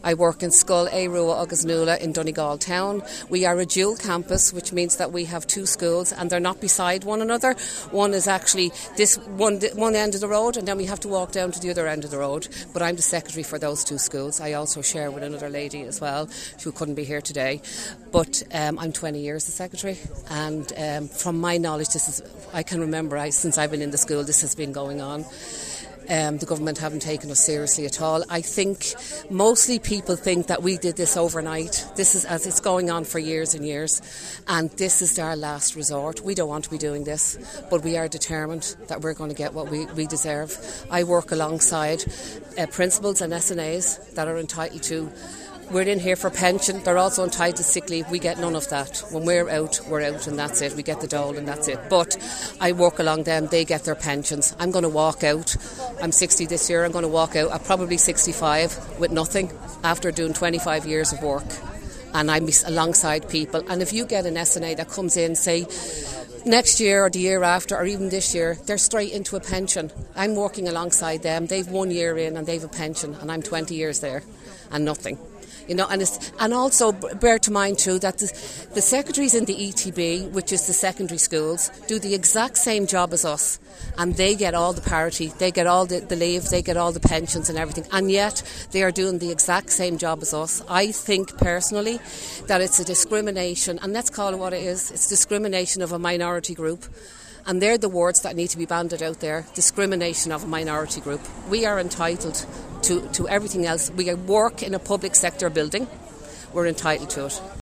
Highland Radio News spoke to those standing out in protest, and three recurring themes came into conversation: guilt, fear, and anger.